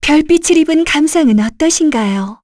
Yuria-Vox_Skill1_kr_b.wav